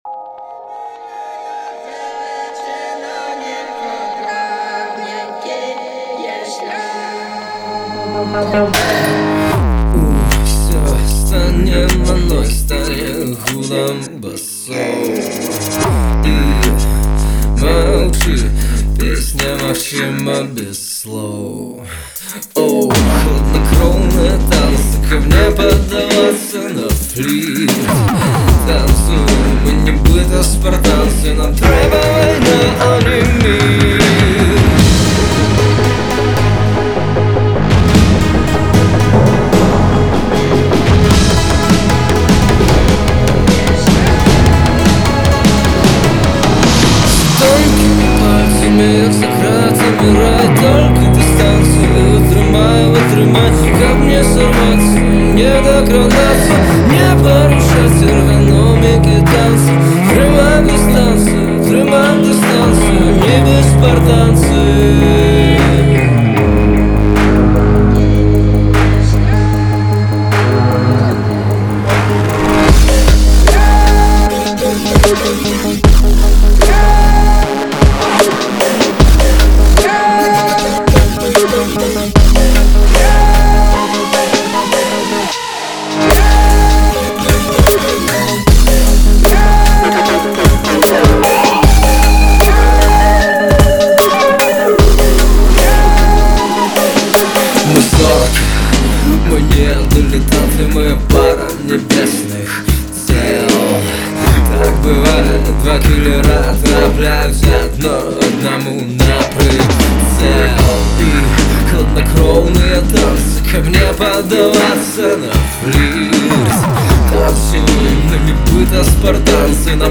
bass-music